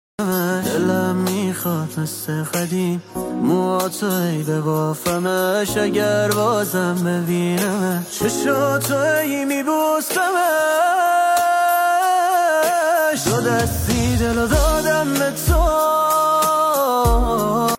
پاپ غمگین عاشقانه عاشقانه غمگین